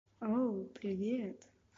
Sonne Hi (message d'accueil vocal)